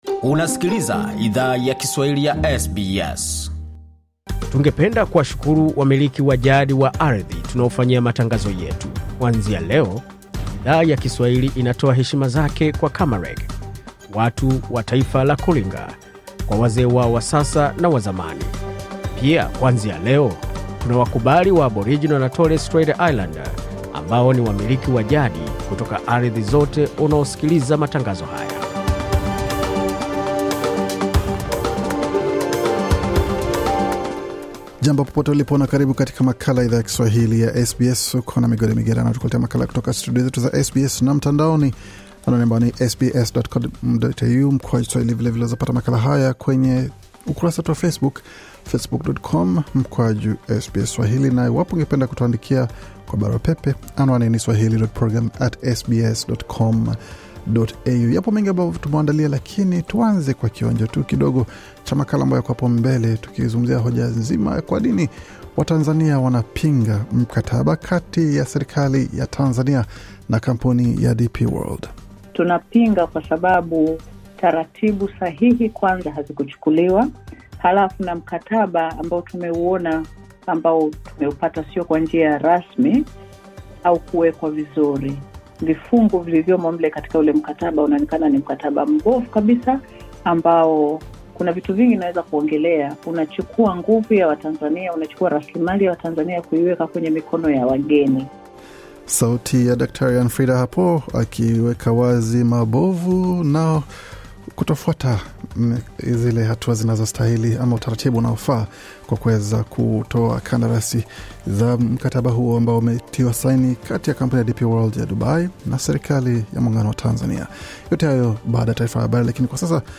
Taarifa ya Habari 25 Julai 2023